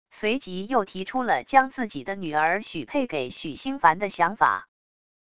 随即又提出了将自己的女儿许配给许星繁的想法wav音频生成系统WAV Audio Player